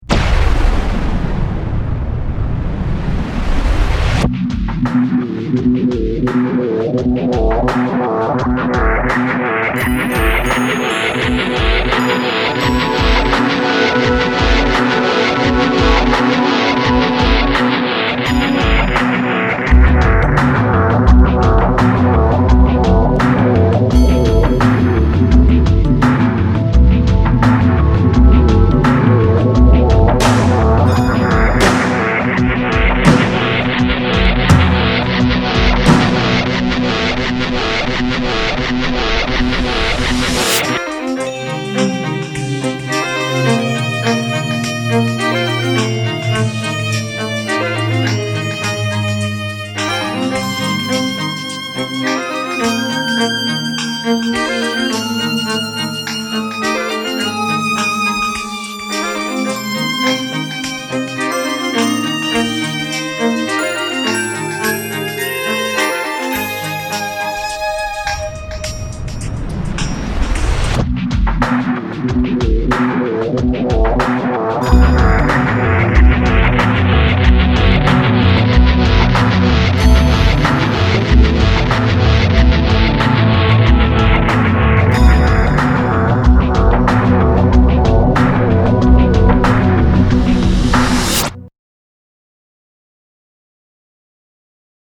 Pop/Rock/New Age